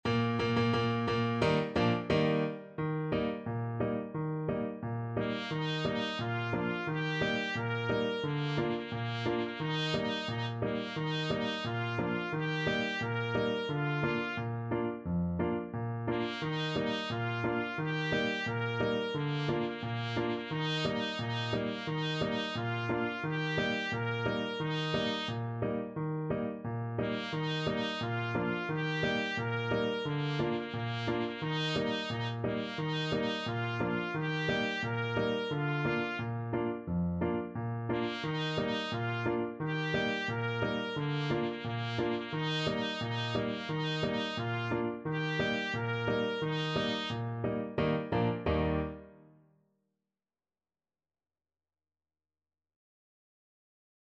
Traditional Trad. Der Guggug uf em duure Ascht Trumpet version
World Europe Switzerland Der Guggug uf em duure Ascht
Trumpet
2/4 (View more 2/4 Music)
Eb major (Sounding Pitch) F major (Trumpet in Bb) (View more Eb major Music for Trumpet )
Steady march =c.88